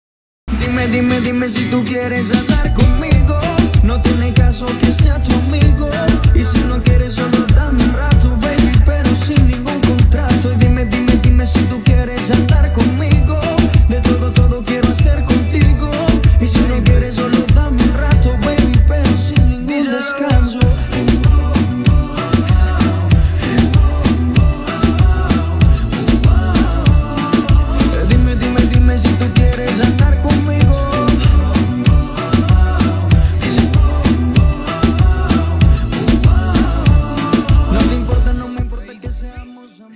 • Качество: 320, Stereo
латина
танцевальные